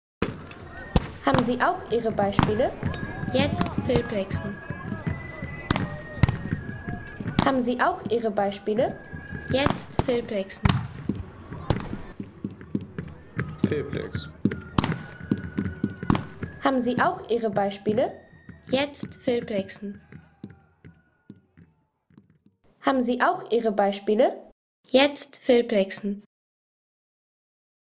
Schüsse aus Handbüchsen und Handröhren